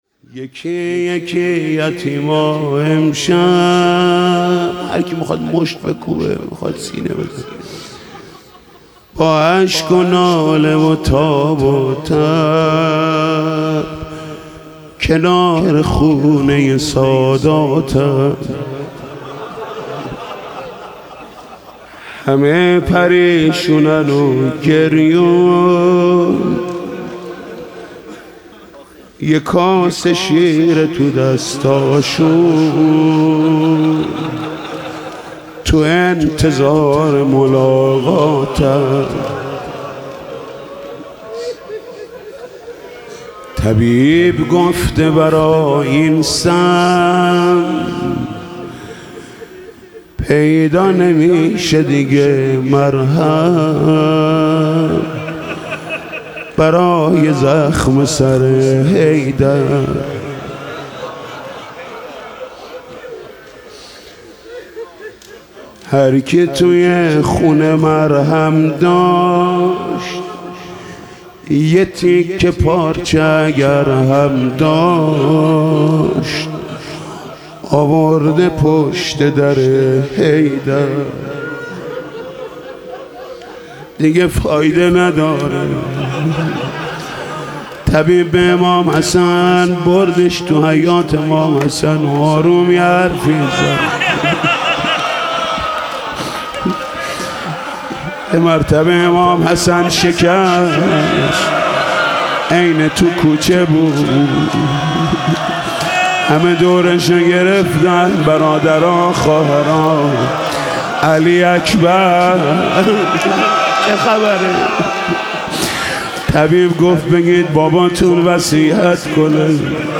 گزارش صوتی شب بیست و یکم ماه رمضان در هیئت رایةالعباس(ع)
بخش اول - روضه ( چون علی در عالم امکان کسی مظلوم نیست )